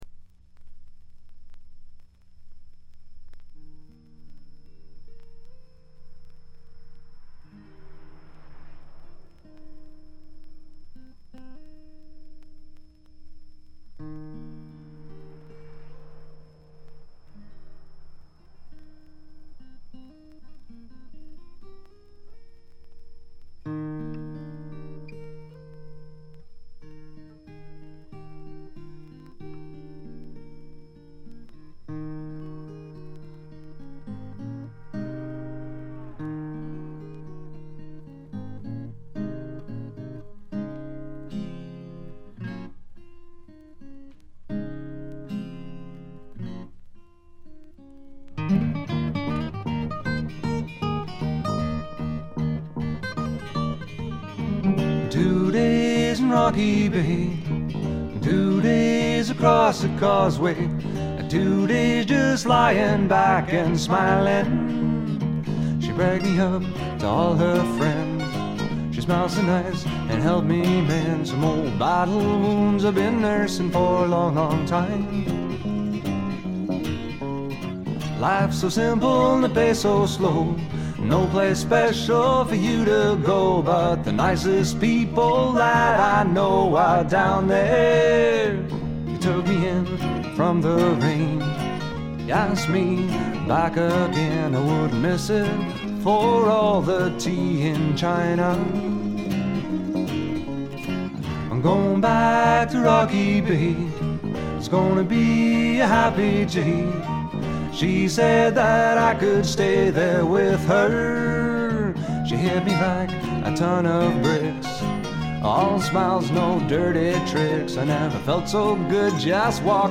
軽微なチリプチほんの少し。
試聴曲は現品からの取り込み音源です。